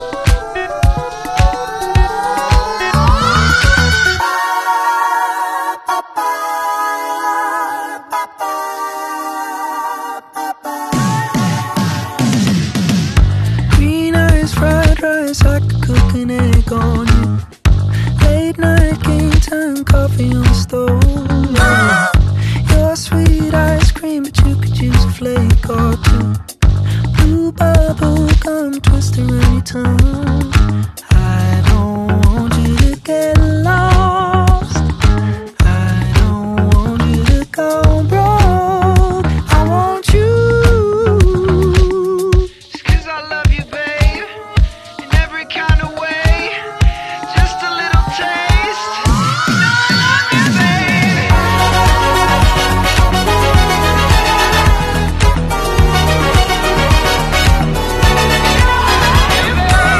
Harry Styles (Full Interview) | sound effects free download